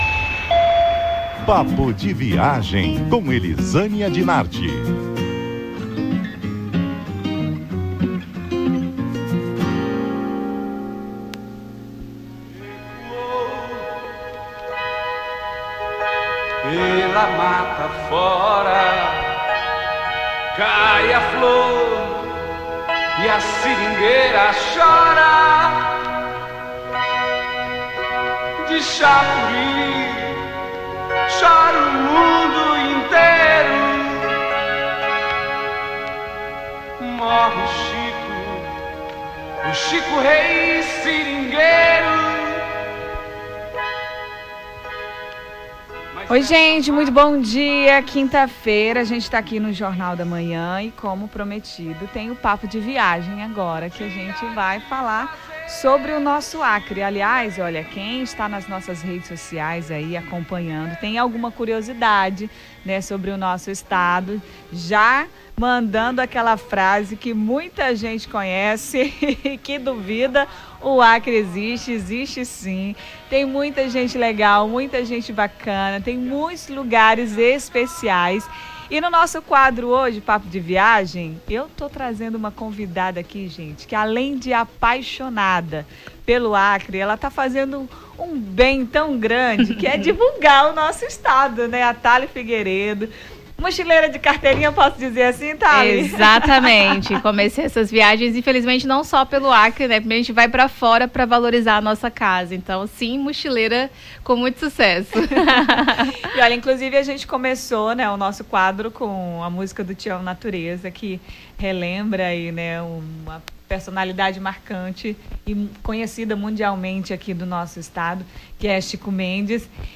QUADRO (PAPO DE VIAGEM)